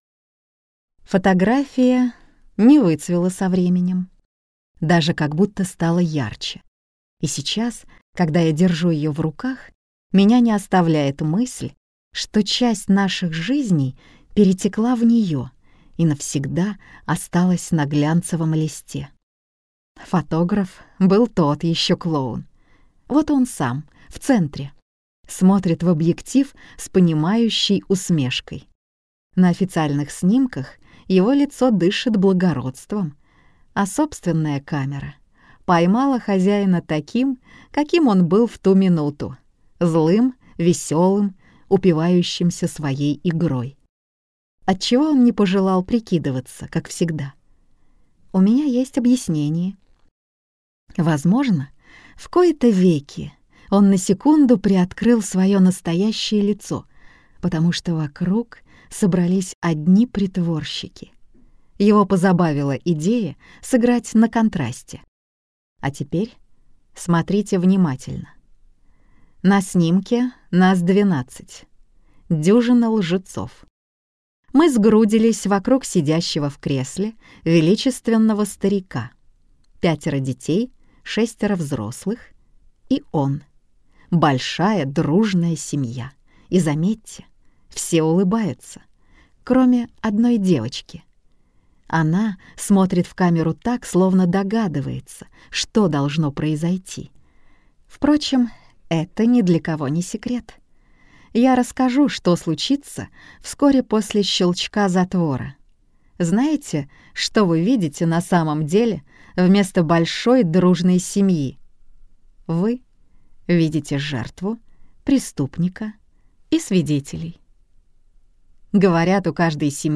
Аудиокнига Пирог из горького миндаля - купить, скачать и слушать онлайн | КнигоПоиск
Прослушать фрагмент аудиокниги Пирог из горького миндаля Елена Михалкова Произведений: 23 Скачать бесплатно книгу Скачать в MP3 Вы скачиваете фрагмент книги, предоставленный издательством